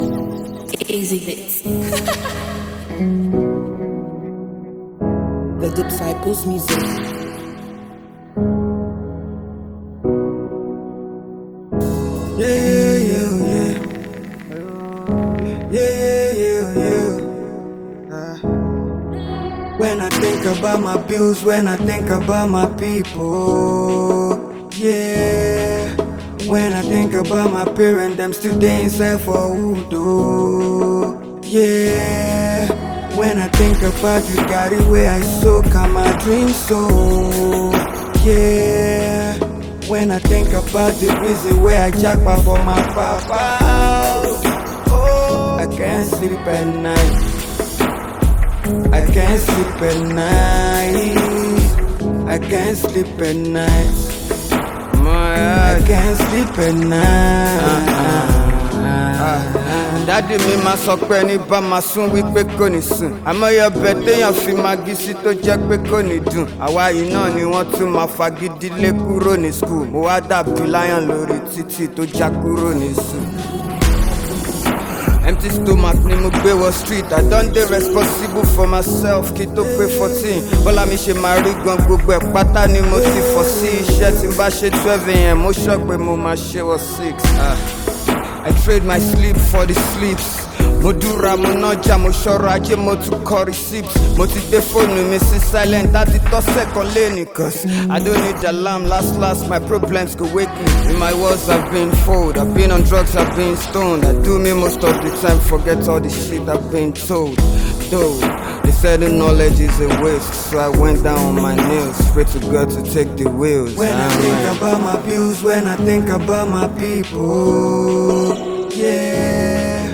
Nigerian rapper and singer